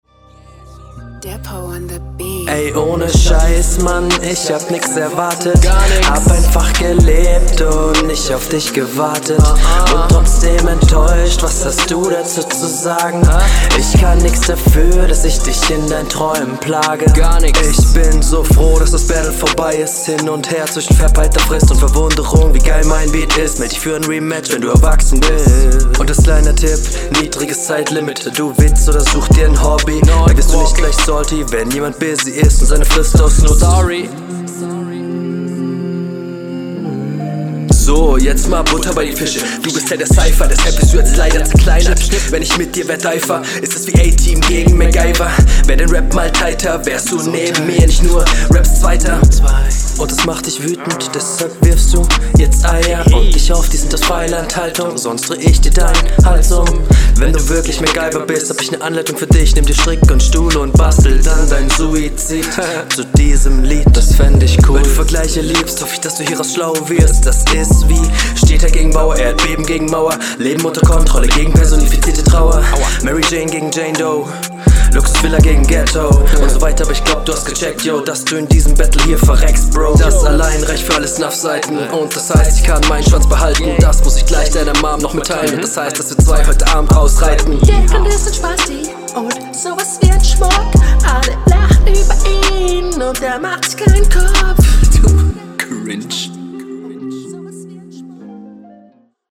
Flowlich und auch von der Soundquali gut.
Hook klingt schonmal viel cooler als bei deinem Gegner.